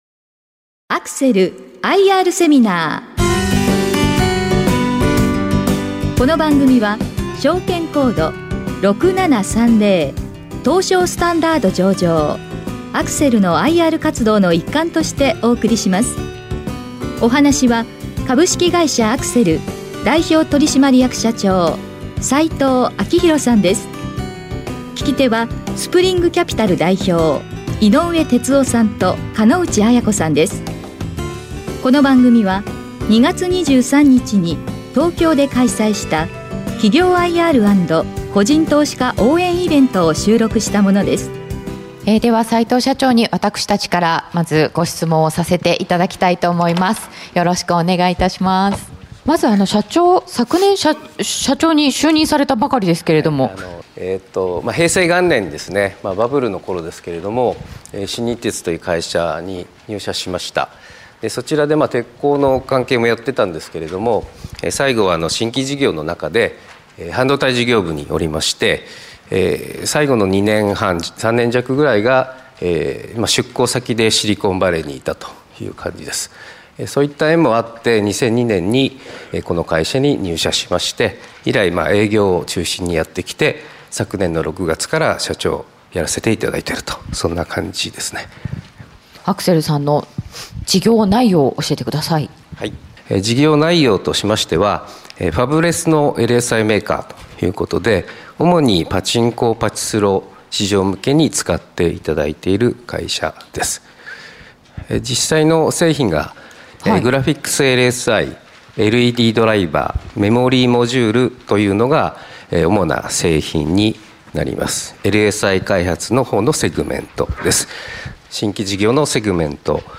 この番組は2月23日に東京で開催したIRセミナーの模様をダイジェストでお届けします。